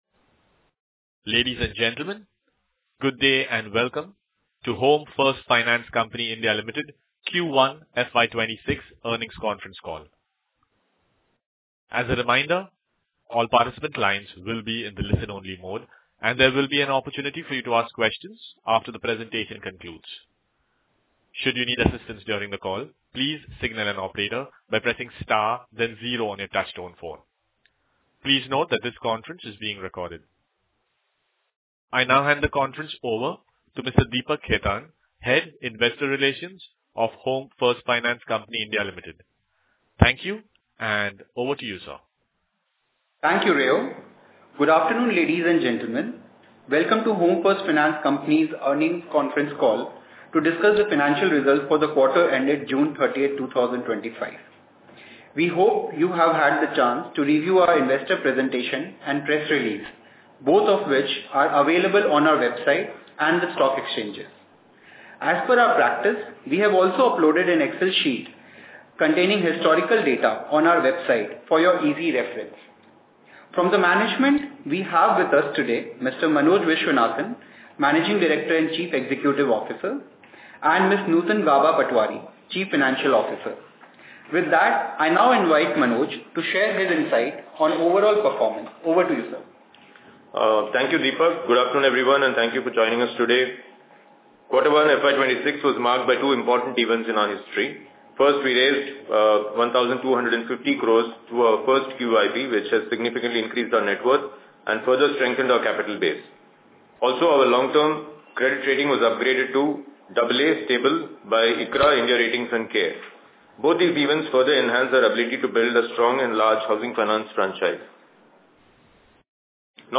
Investor Call Audio